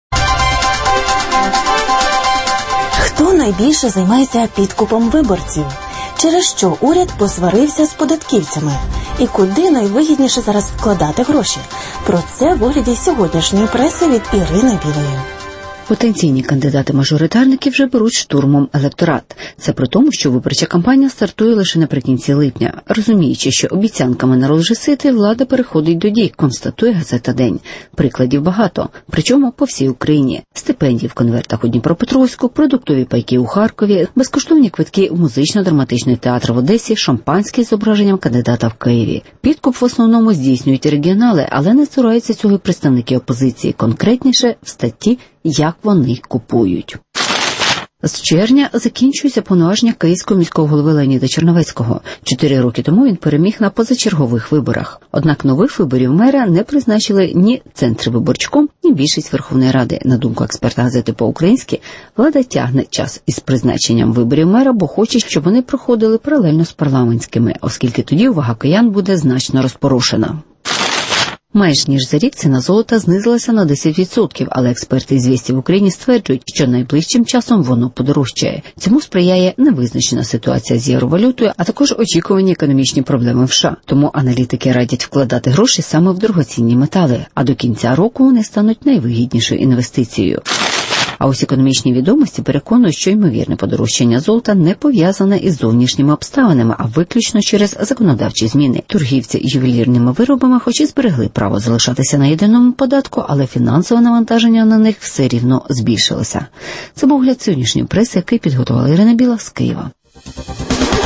Підкуп виборців посилюється (огляд преси)